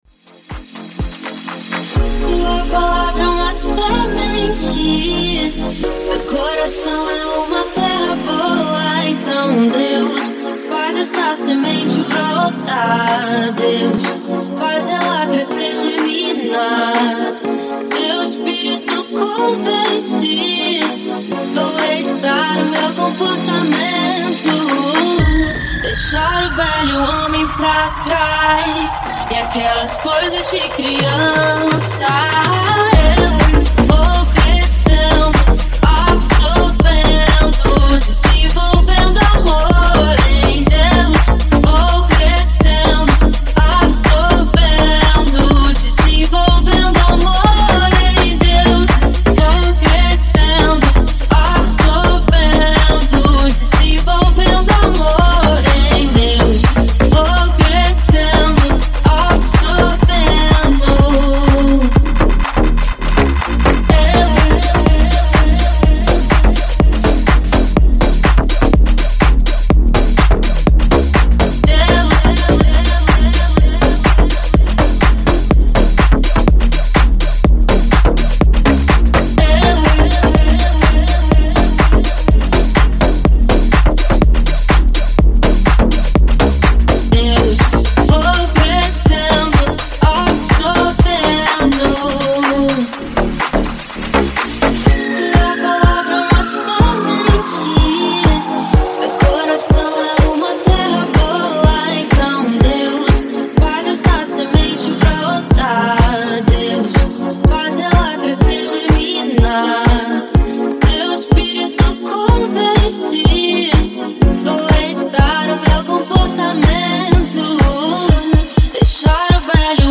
Gênero Gospel.